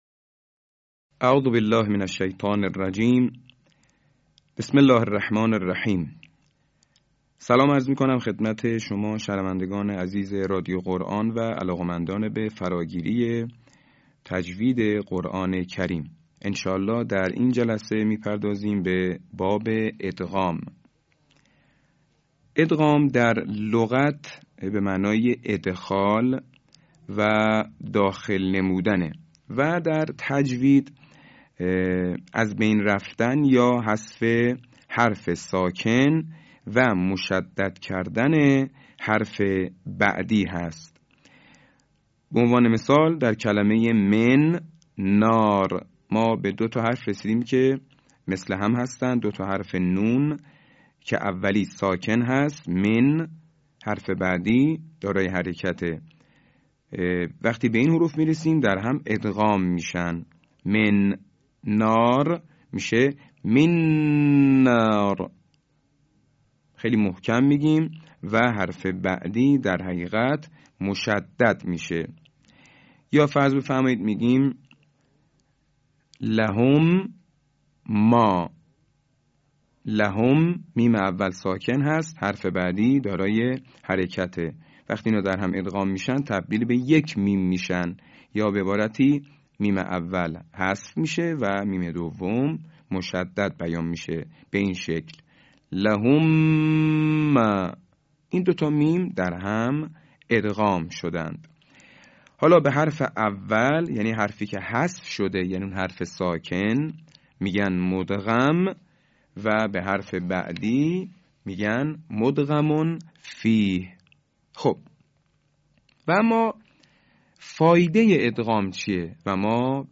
صوت | آموزش ادغام در تجوید